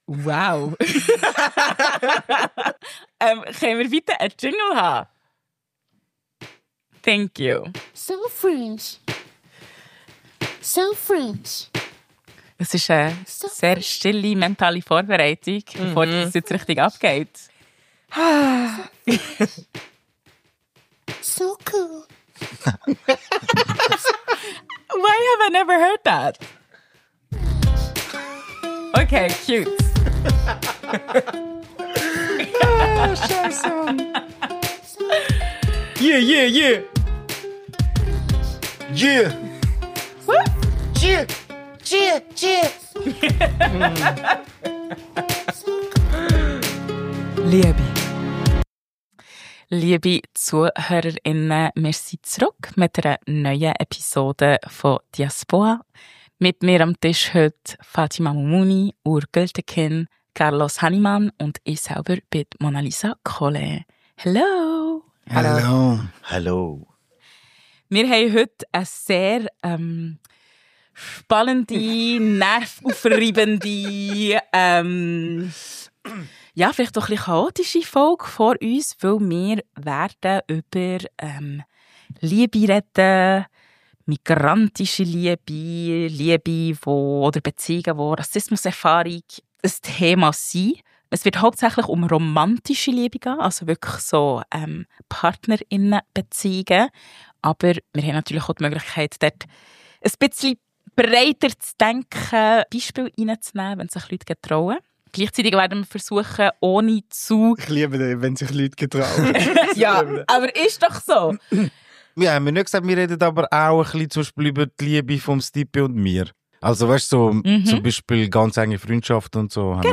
Welchen Einfluss haben Herkunft, Eltern, Religion und Klasse auf Begehren, Partner*innenwahl und Beziehungsideale? Ein Gespräch zwischen persönlichen Anekdoten und politischen Reflexionen zur Liebe in Zeiten der post-migrantischen Schweiz.